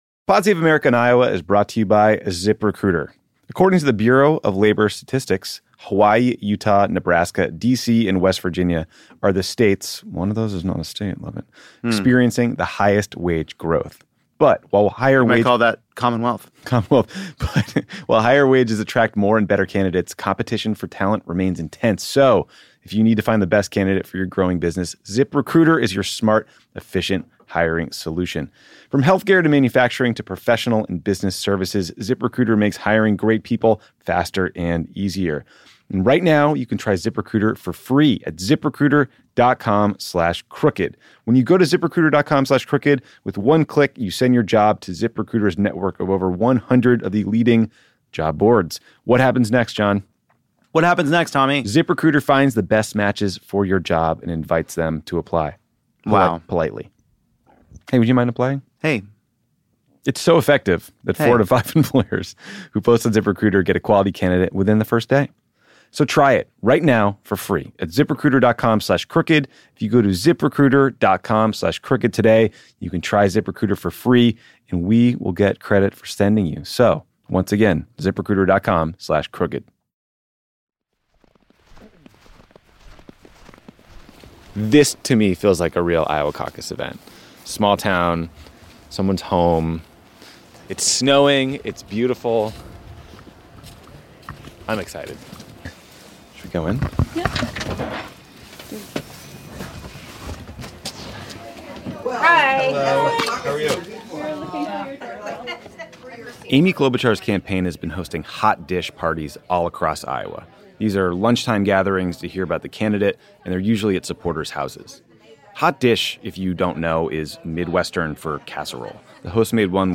This series is hosted by Tommy Vietor and produced by Pineapple Street Studios.